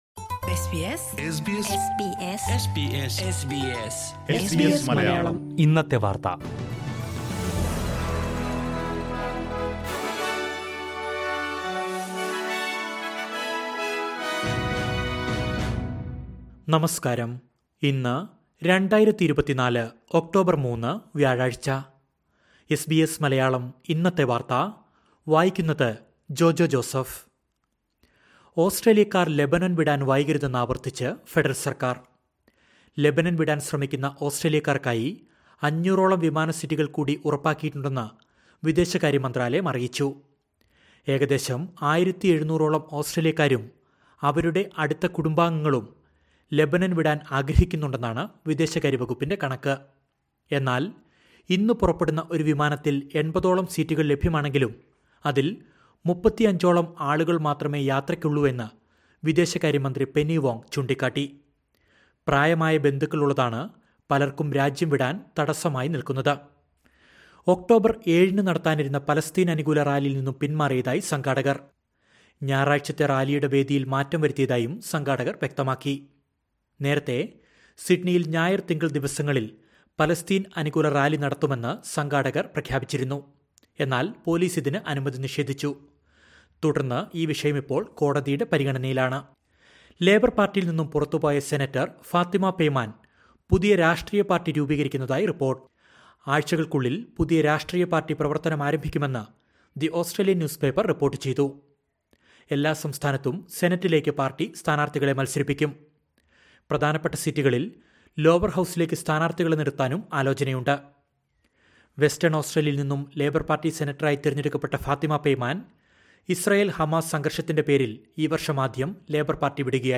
2024 ഒക്ടോബര്‍ മൂന്നിലെ ഓസ്‌ട്രേലിയയിലെ ഏറ്റവും പ്രധാന വാര്‍ത്തകള്‍ കേള്‍ക്കാം...